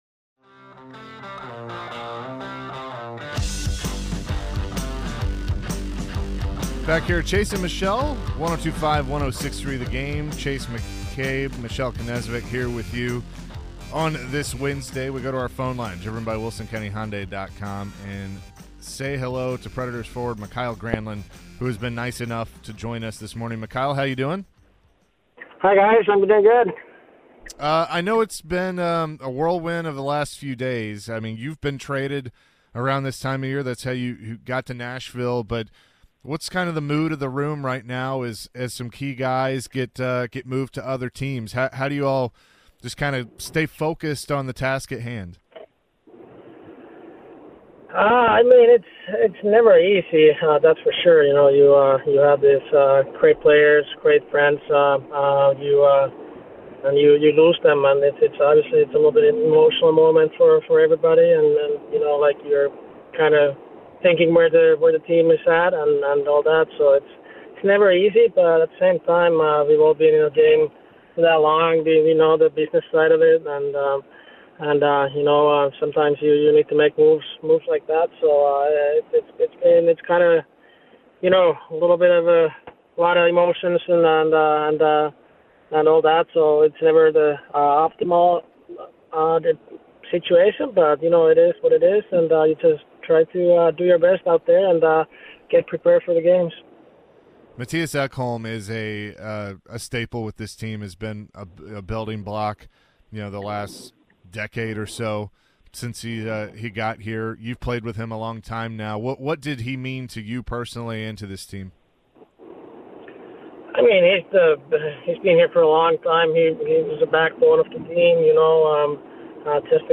Mikael Granlund Interview (3-1-23)